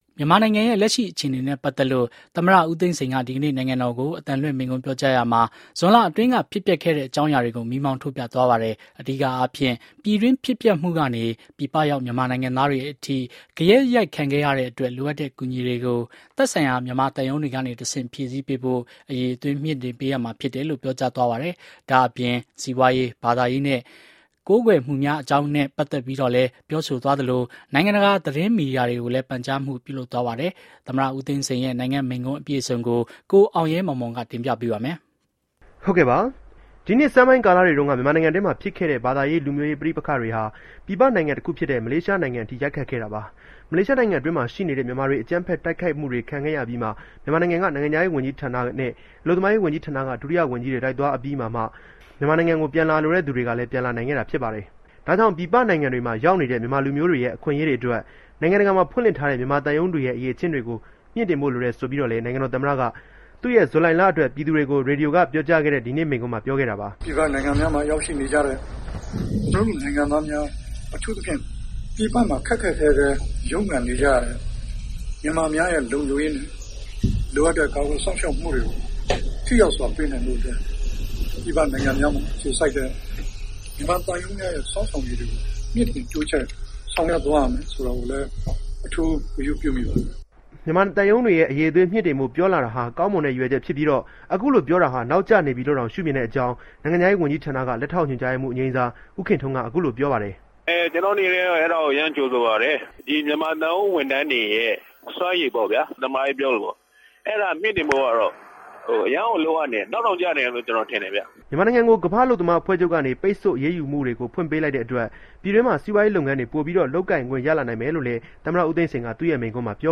သမ္မတဦးသိန်းစိန်မိန့်ခွန်း